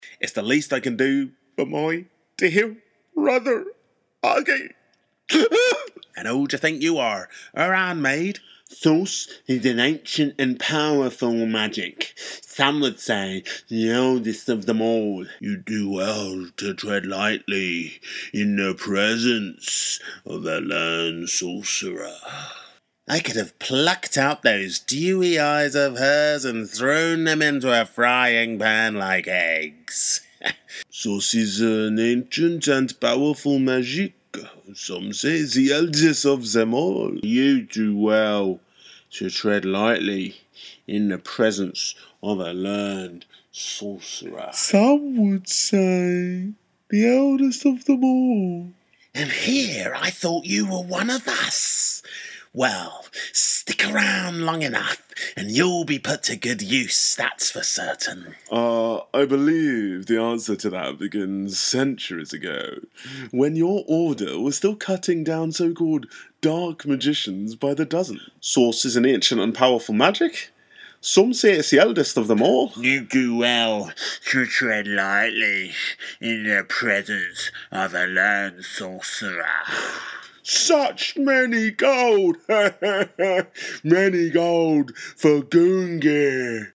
• Native Accent: London, RP, African